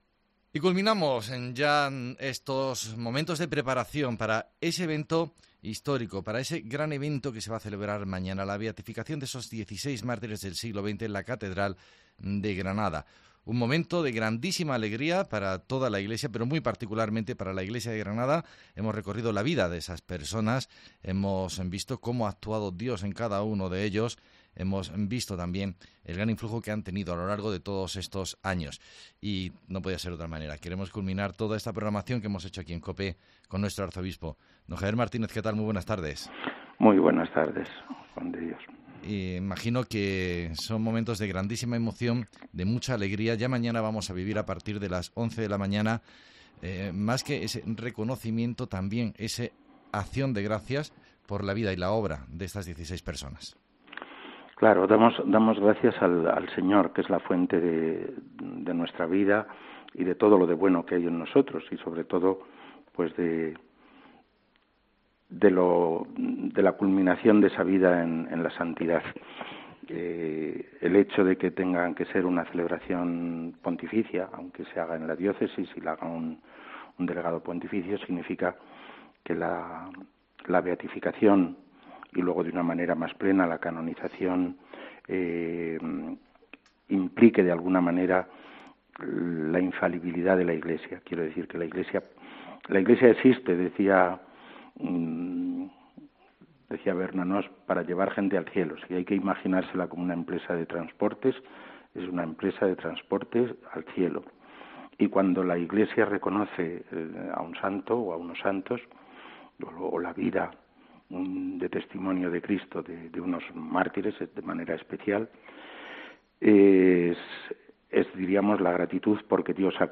El arzobispo nos habla del significado de la beatificación que viviremos el sábado en la catedral y que podrán seguir por TRECE TV y por la televisión diocesana VATELEVISIÓN